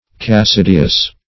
Search Result for " cassideous" : The Collaborative International Dictionary of English v.0.48: Cassideous \Cas*sid"e*ous\, a. [L. Cassis helmet.]
cassideous.mp3